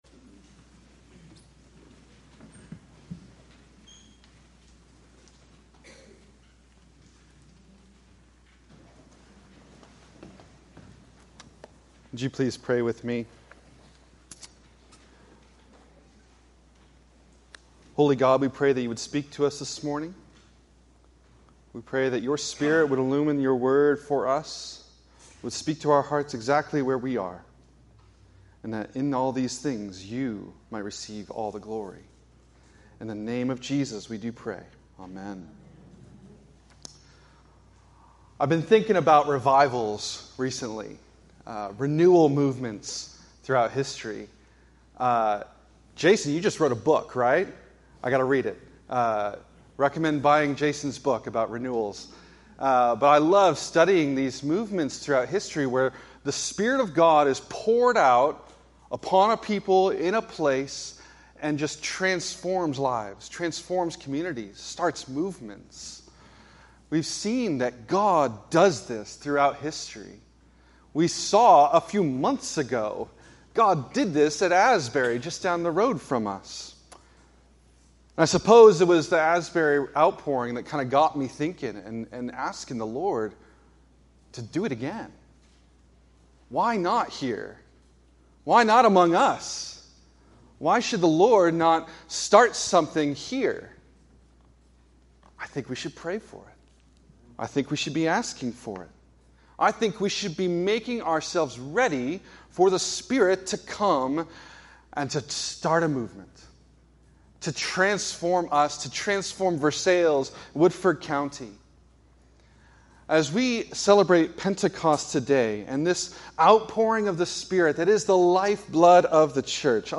A Pentecost Sermon